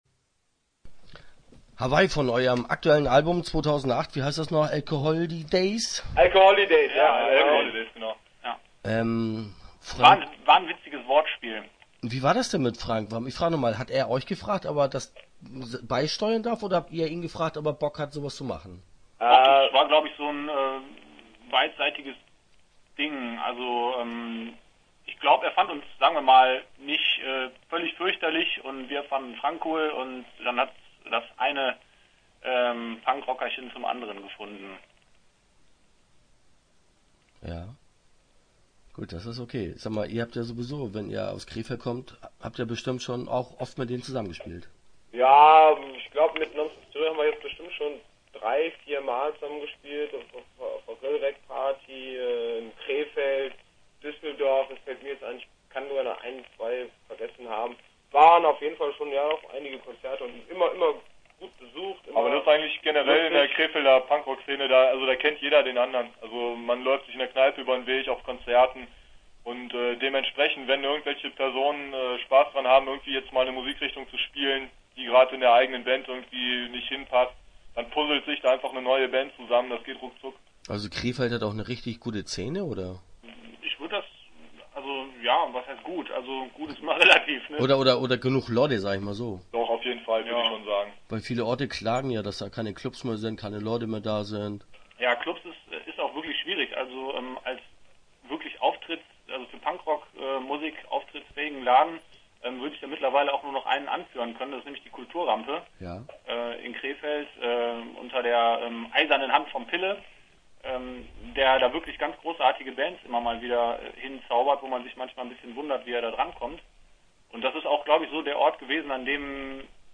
Start » Interviews » Incoming Leergut